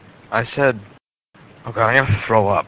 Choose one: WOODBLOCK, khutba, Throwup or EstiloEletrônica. Throwup